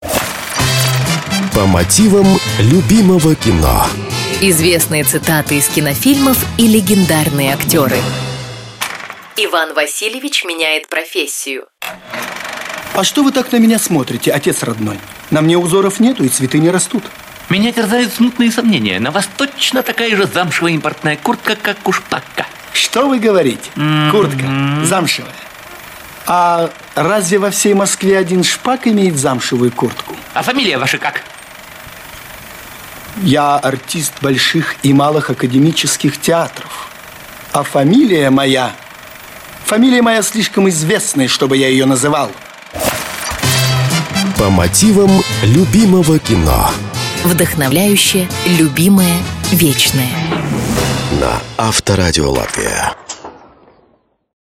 В этой программе вы услышите знаменитые цитаты из кинофильмов, озвученные голосами легендарных актеров.